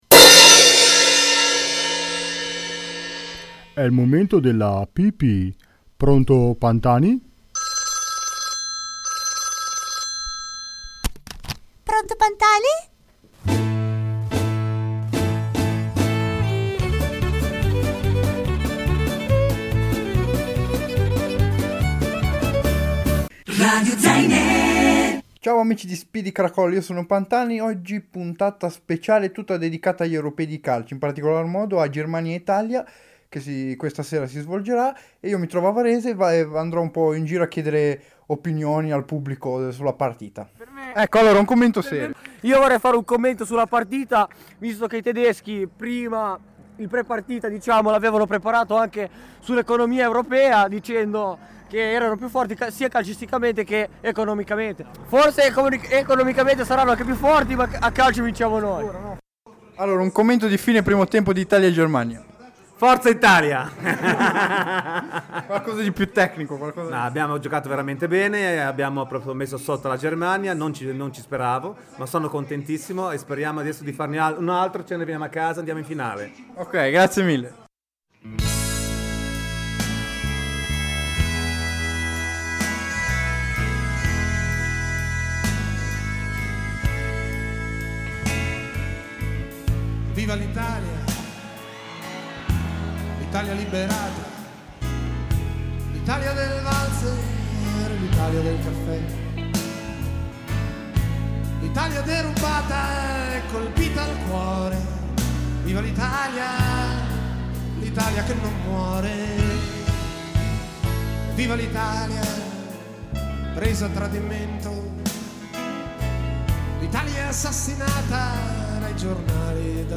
Puntata dedicata e registrata in diretta durante la semifinale Germania-Italia degli Europei di Calcio 2012 Polonia-Ucraina